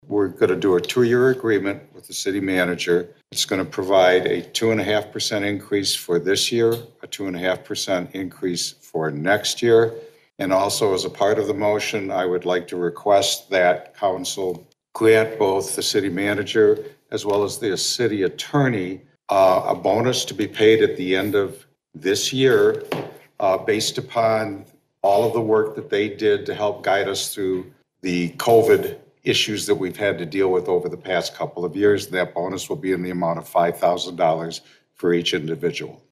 Council member John Petzko made the motion.